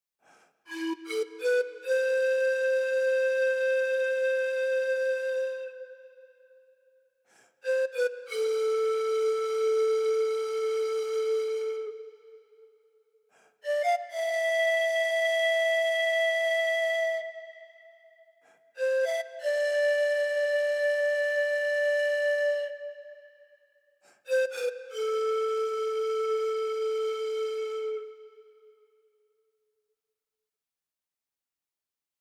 Chromatic Pan Flute features a large Peruvian pan flute, ready to play right away.
The soft dynamic layer is triggered by gentle playing with low velocity on your keyboard.
Listen to – Soft Dynamic Layer